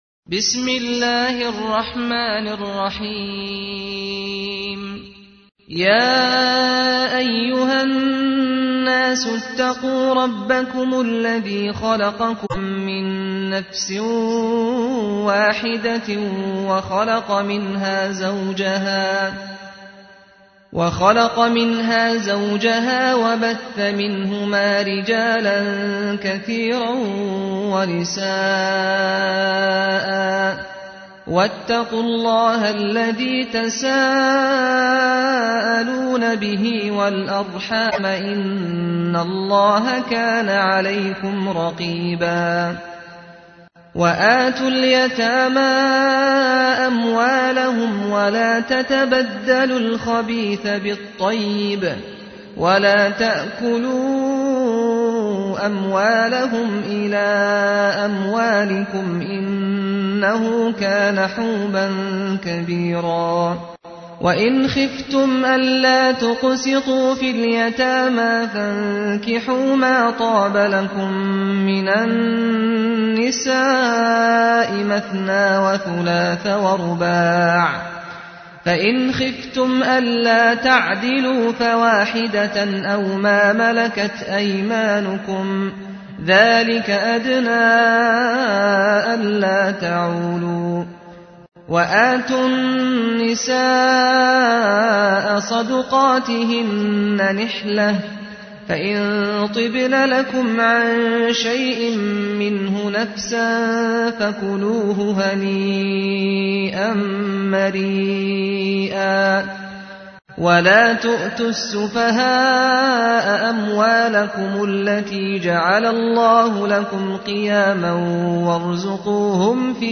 تحميل : 4. سورة النساء / القارئ سعد الغامدي / القرآن الكريم / موقع يا حسين